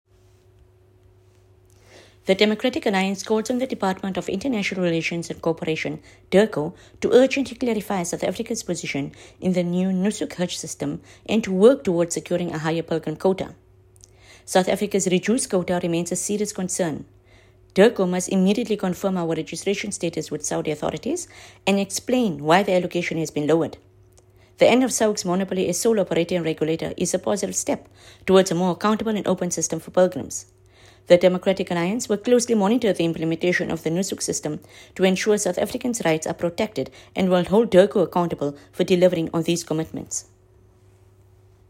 soundbite by Haseena Ismail MP.